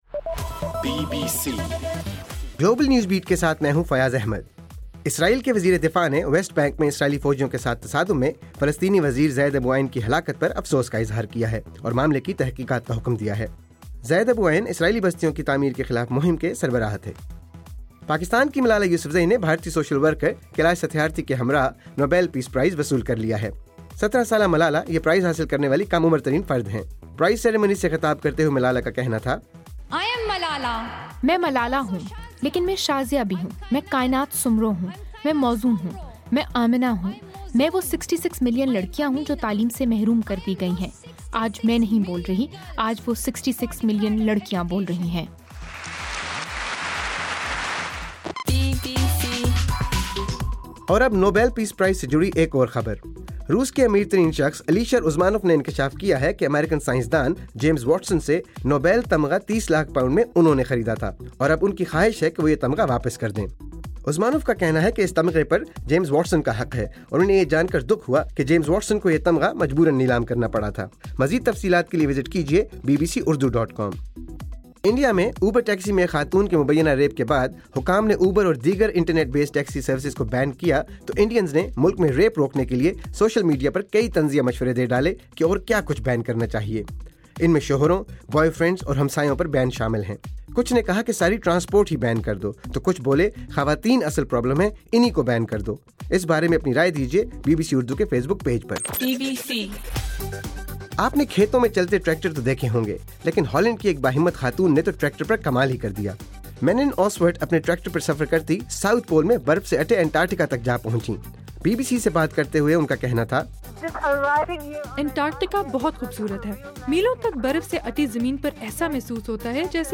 دسمبر 11: صبح 1 بجے کا گلوبل نیوز بیٹ بُلیٹن